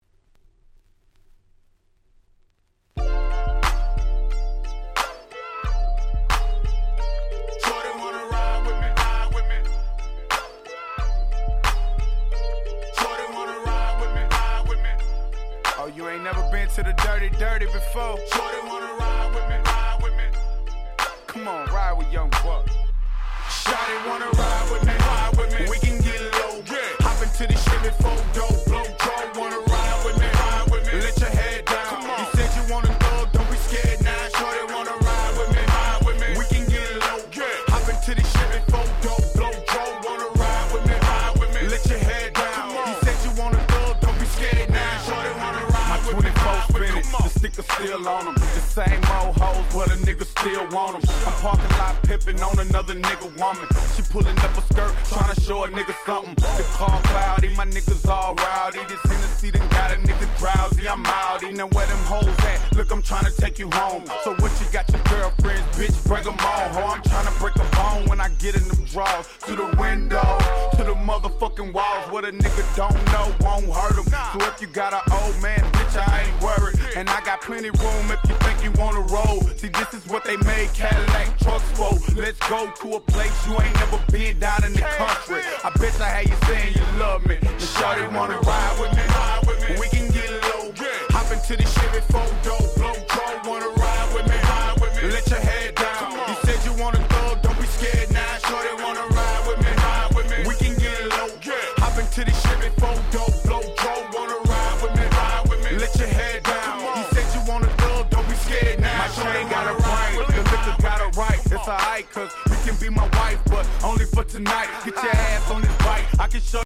04' Smash Hit Hip Hop !!